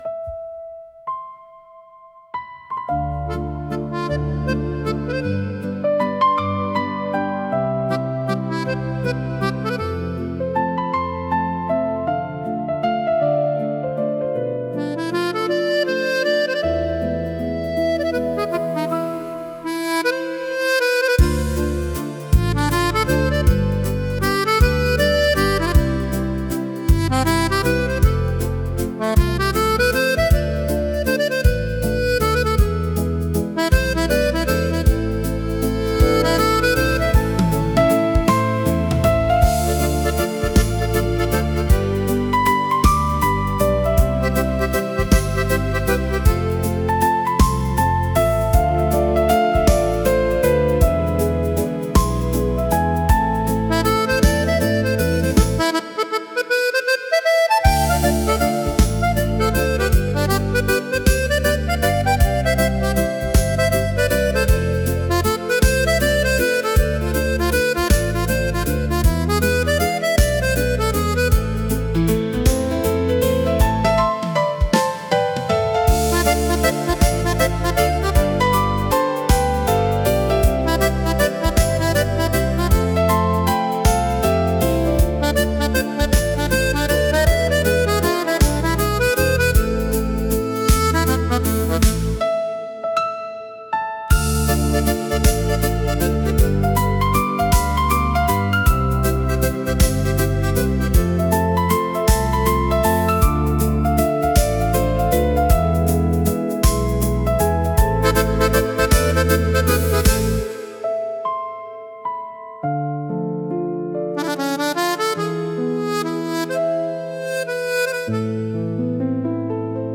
• Качество: Хорошее
• Жанр: Детские песни
Музыка светлой грусти и надежды